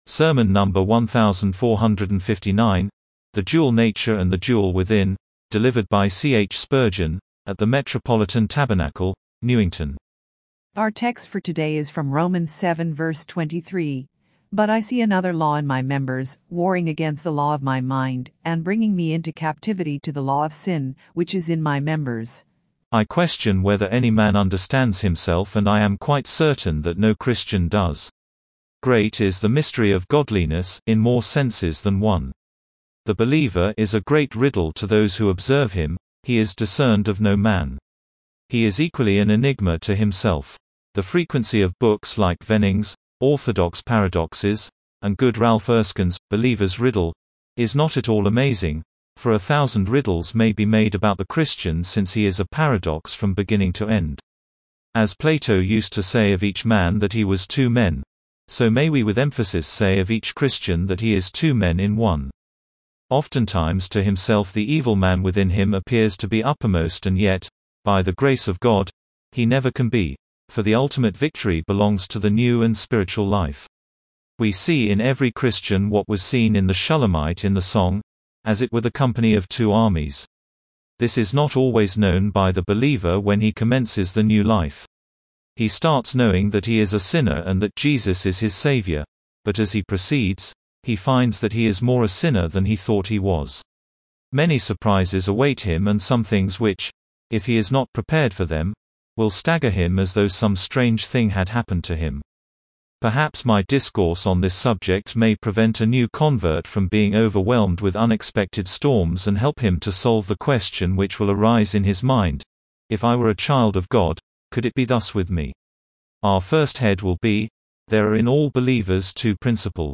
Sermon number 1,459, THE DUAL NATURE AND THE DUEL WITHIN